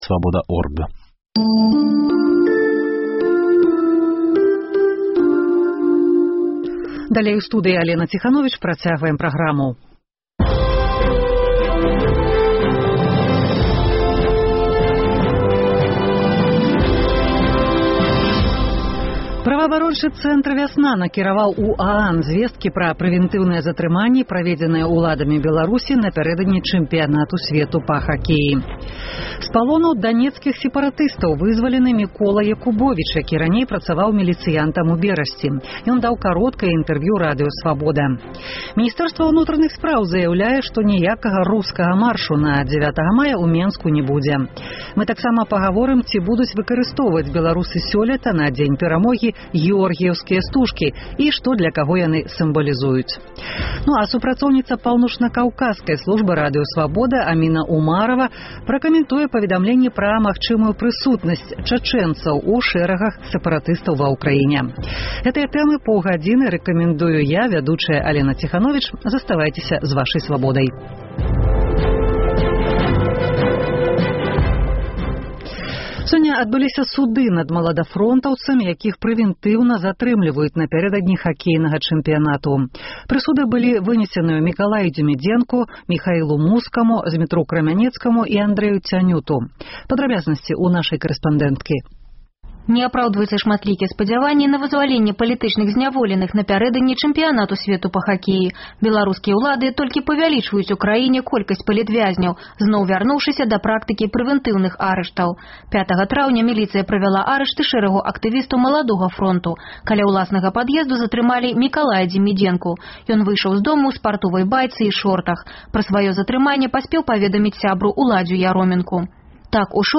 Vox populi: зь якіх СМІ вы атрымліваеце інфармацыю пра падзеі ва Ўкраіне?